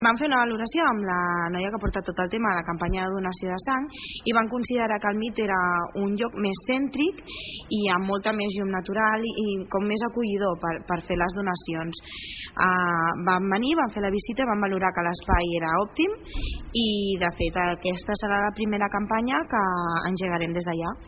En parla la regidora de Salut de l’Ajuntament de Palafolls, Sònia González.